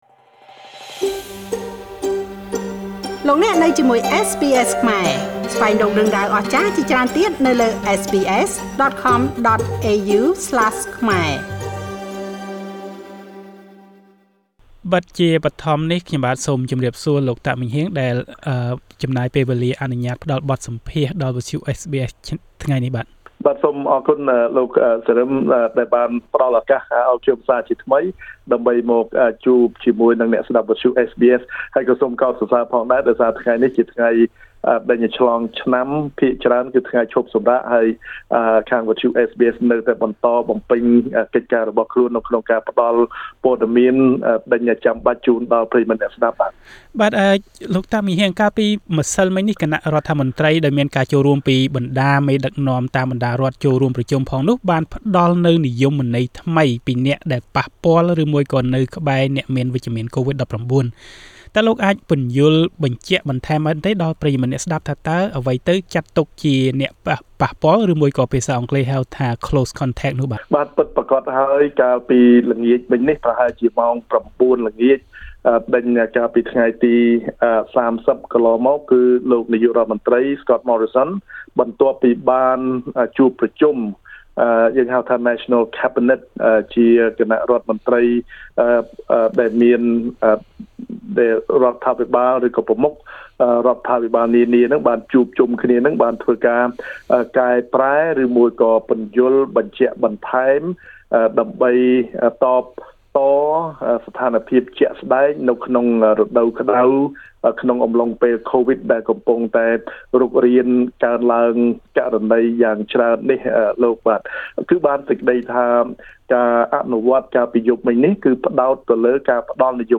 តទៅនេះសូមស្តាប់ការបកស្រាយរបស់លោក តាក ម៉េងហ៊ាង សមាជិកសភារដ្ឋវិកថូរៀដូចតទៅ ៖